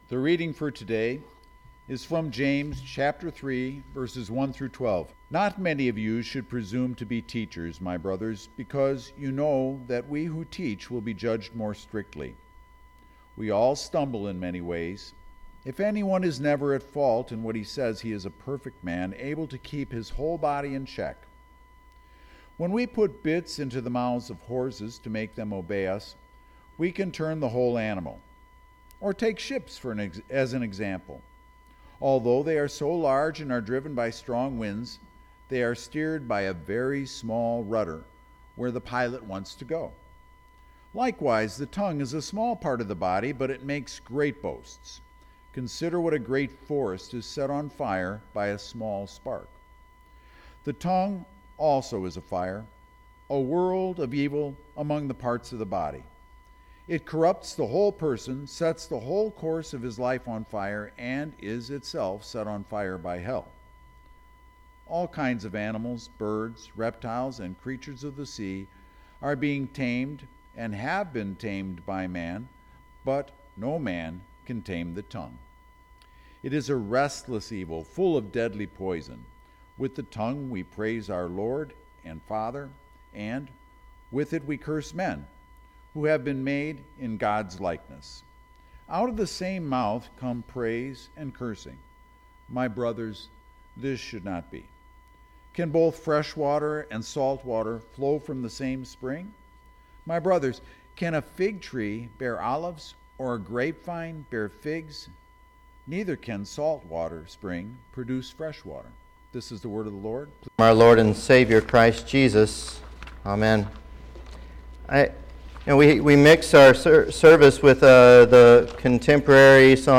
Sermons - Holy Cross Lutheran Church